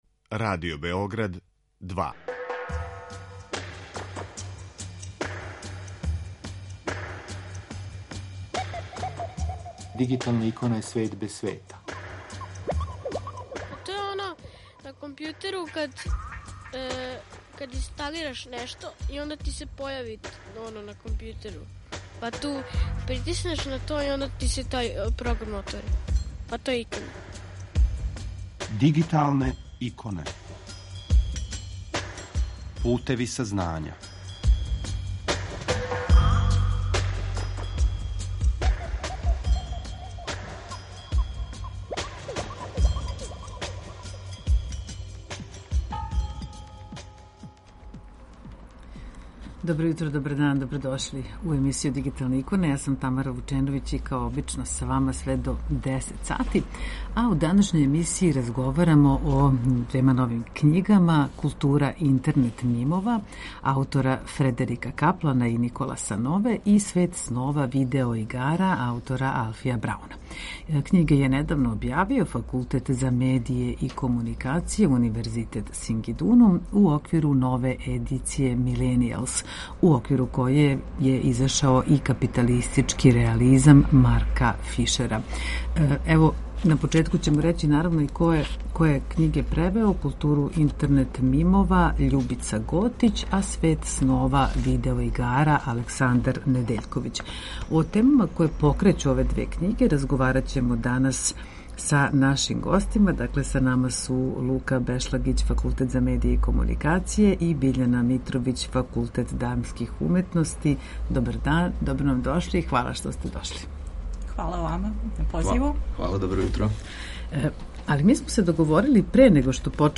Са нама уживо: